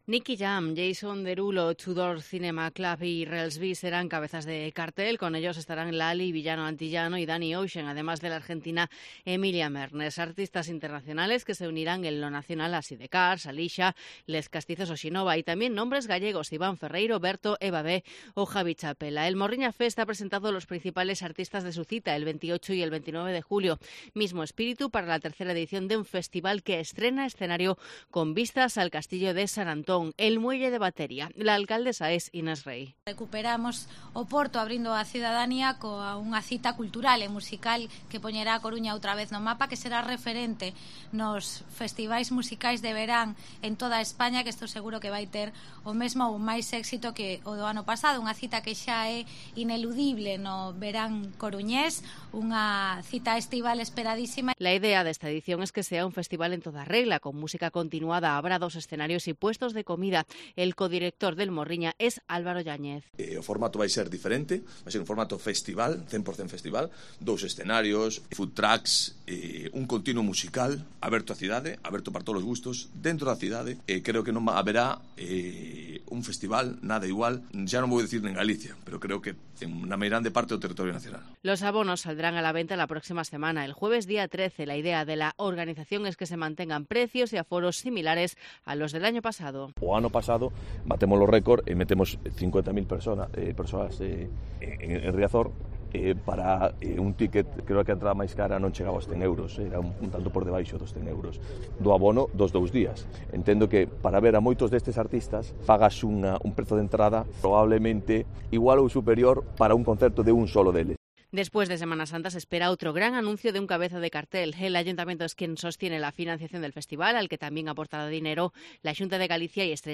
Crónica de la presentación del Morriña Fest 2023